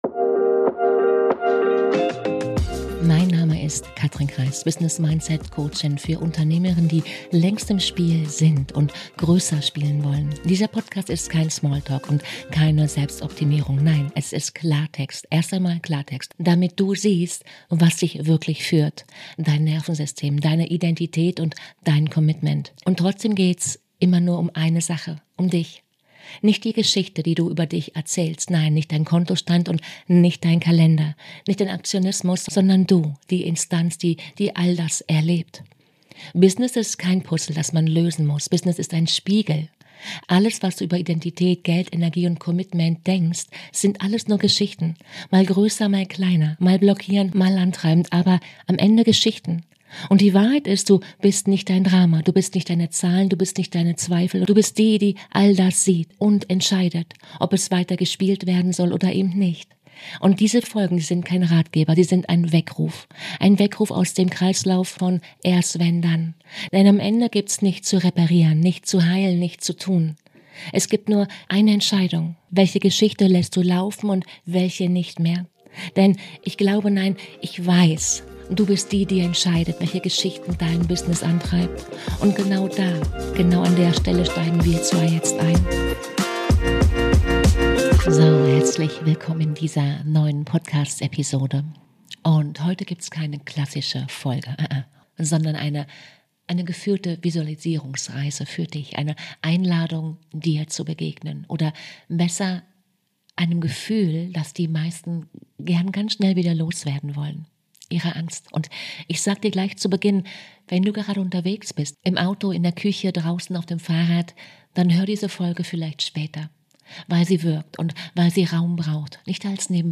Angst halten, ohne sie zu heilen - Meditation ~ Unsichtbar war Gestern Podcast
Beschreibung vor 5 Monaten 436 | Heute gibt’s keine klassische Episode, sondern eine geführte Visualisierungsreise – eine Einladung, dir zu begegnen. Dir – und einem Gefühl, das die meisten gern schnell wieder loswerden wollen: Angst. Diese Reise ist kein Hypnose-Format.